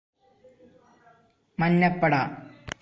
Manjappada (transl. Yellow Army; Malayalam: [mɐɲːɐpːɐɖɐ]